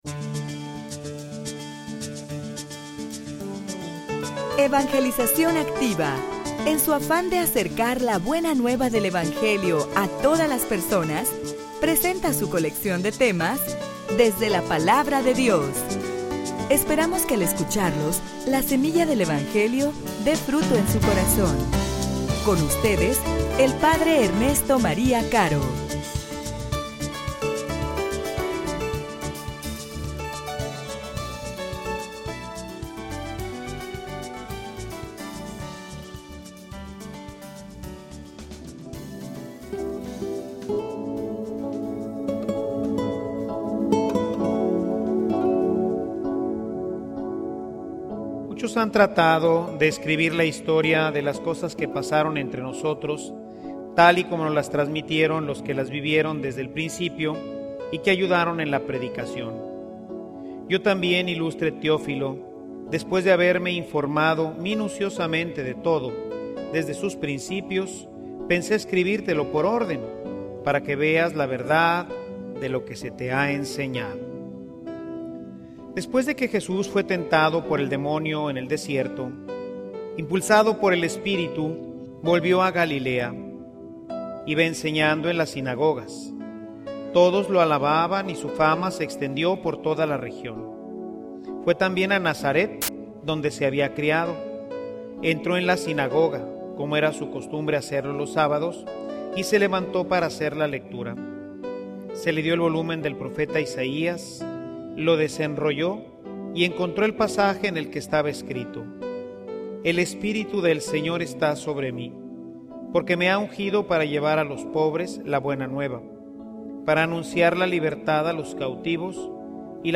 homilia_La_Palabra_de_Dios_un_instrumento_para_la_vida.mp3